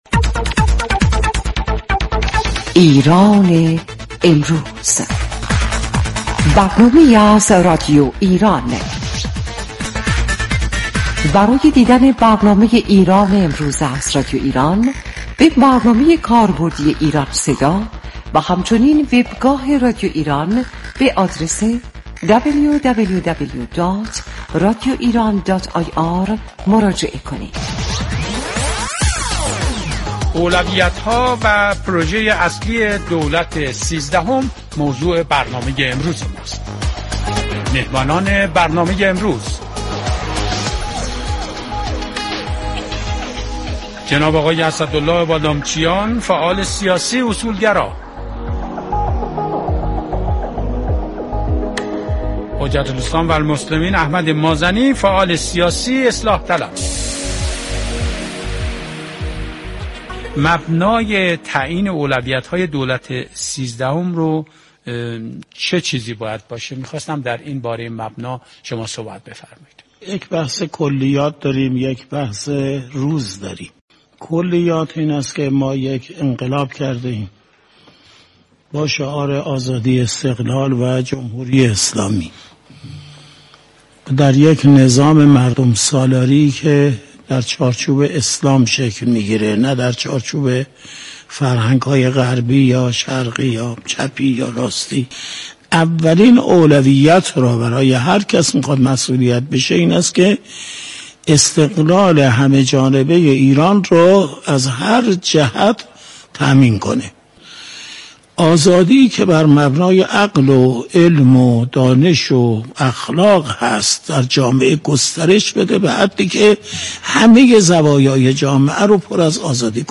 در یازدهمین برنامه انتخاباتی «ایران امروز»، سه شنبه چهارم خرداد، رادیو ایران میزبان اسدالله بادامچیان، فعال سیاسی اصولگرا و حجت‌الاسلام والمسلمین احمد مازنی، فعال سیاسی اصلاح‌طلب بود.